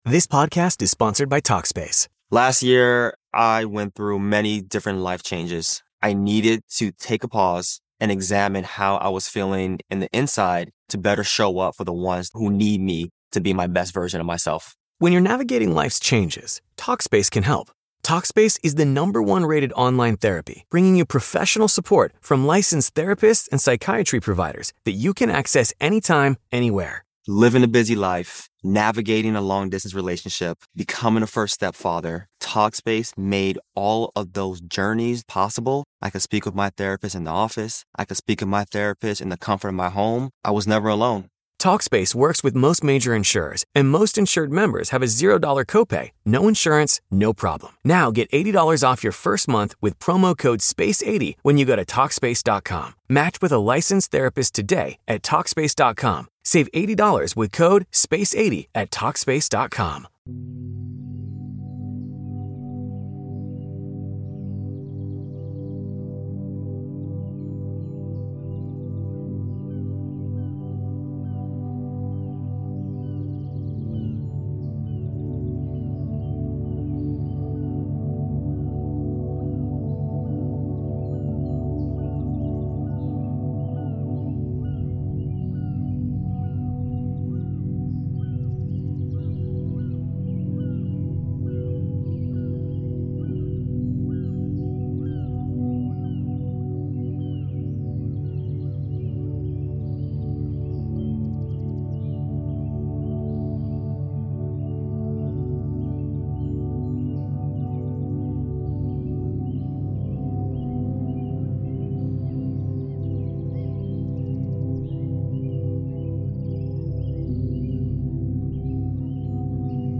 50hz- Gamma Binaural Beats - Concentration and Focus
Calm Chic sound rituals — a softer way to focus.